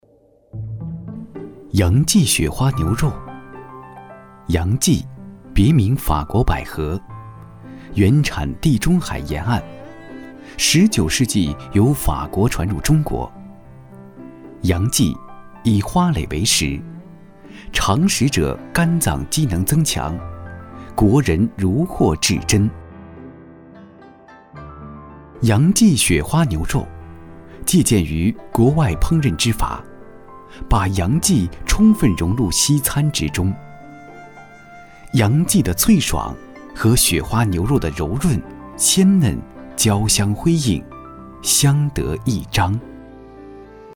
轻松自然 舌尖美食
自然轻松，磁性男音，擅长专题解说、产品解说、纪录片解说等题材。